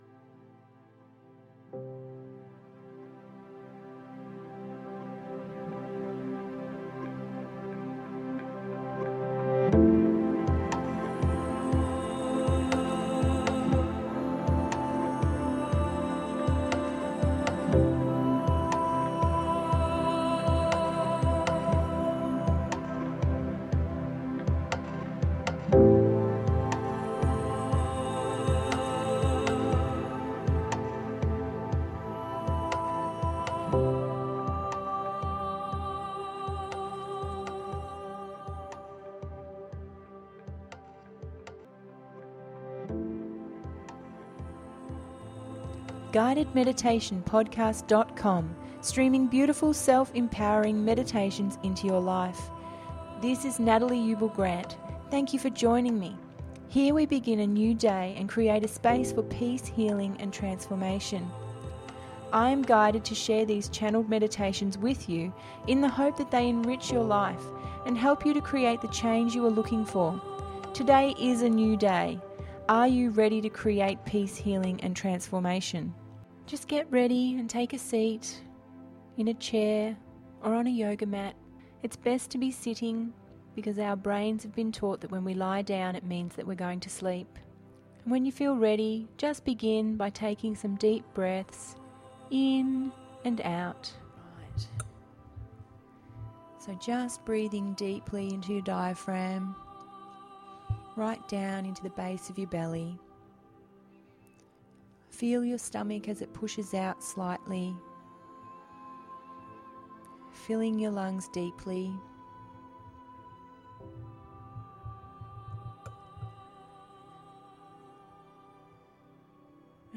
Heart Temple Wish…021 – GUIDED MEDITATION PODCAST